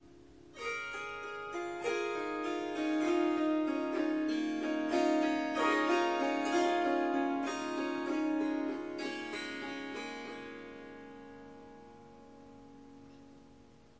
Swarmandal印度竖琴曲谱 " 竖琴弹奏与弹奏11
这个奇妙的乐器是Swarmandal和Tampura的结合。
它被调到C sharp，但我已经将第四个音符（F sharp）从音阶中删除了。
这些片段取自三天不同的录音，因此您可能会发现音量和背景噪音略有差异。一些录音有一些环境噪音（鸟鸣，风铃）。
Tag: 竖琴 弦乐 旋律 Swarsangam 民族 Swarsangam 印度 即兴重复段 Surmandal Swarmandal 旋律